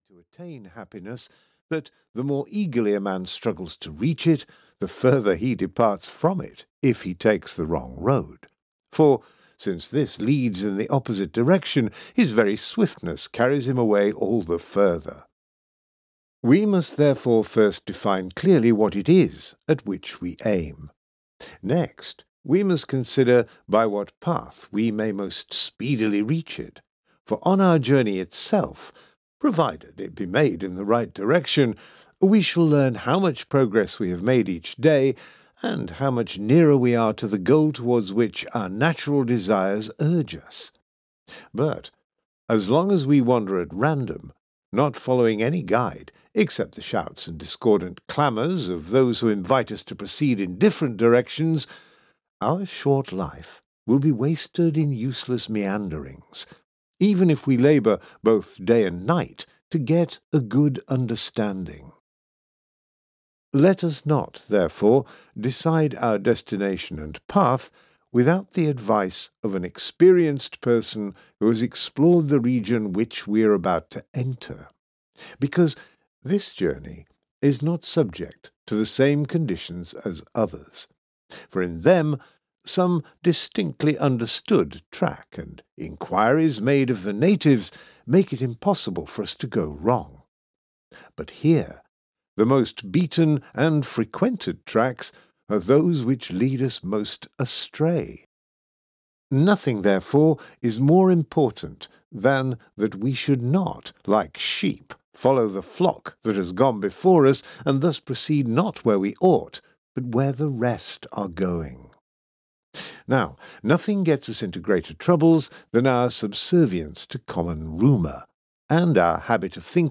On the Happy Life – The Complete Dialogues (EN) audiokniha
Ukázka z knihy